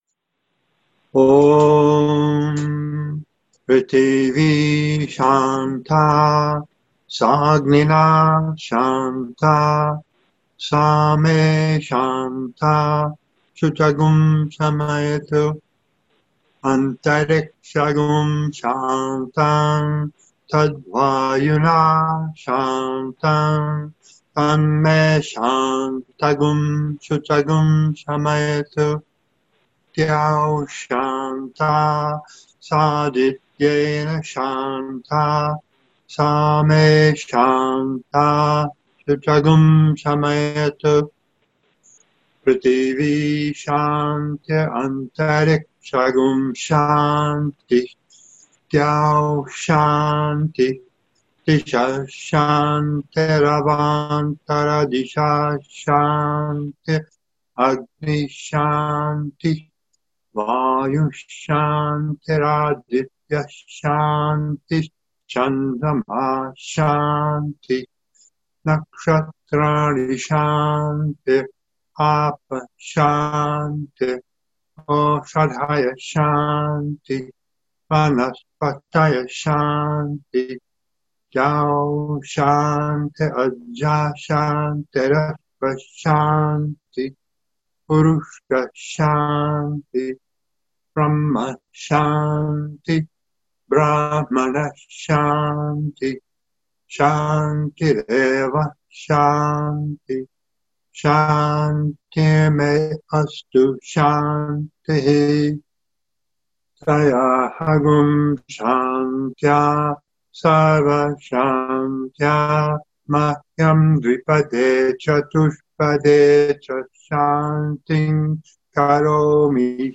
mahāśāntiḥ mantra sanskrit recitation